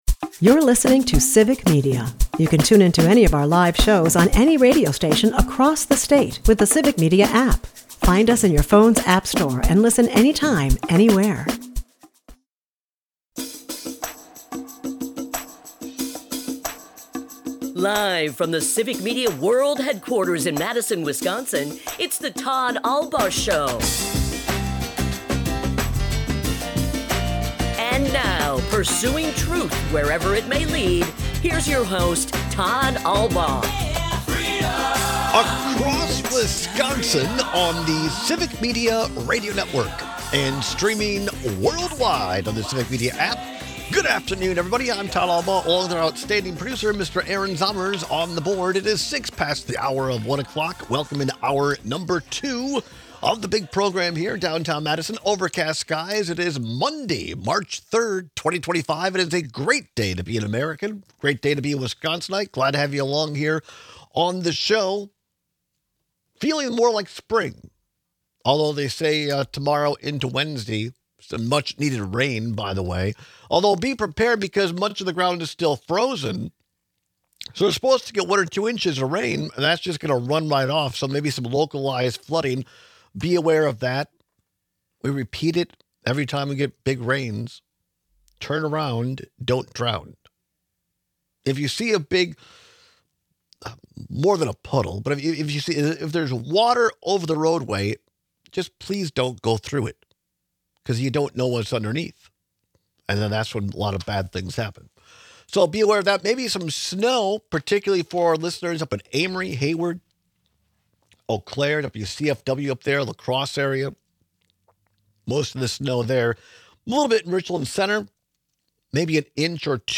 We take calls and texts on whether you’d rather be sitting in a puddle of sweat or suffer from dry skin and bloody noses.